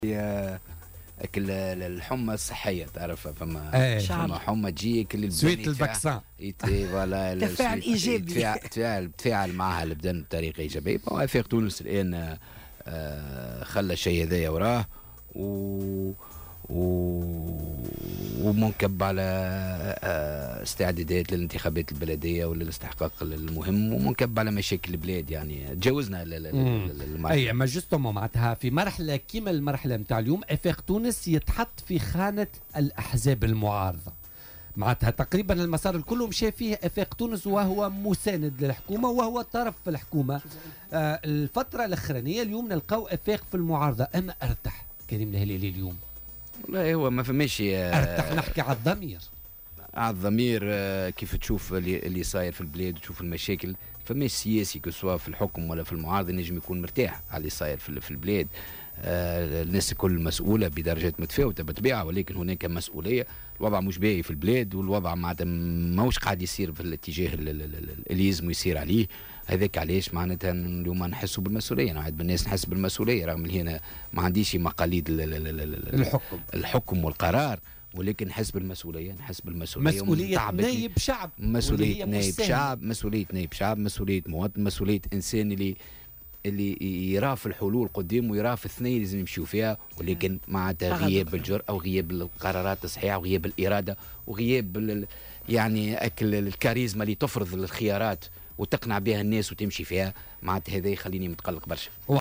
وأضاف في مداخلة له اليوم في برنامج "بوليتيكا" أن الحزب ترك كل هذه الخلافات وراءه وهو منكب حاليا على التحضير والاستعداد للانتخابات البلدية المقبلة.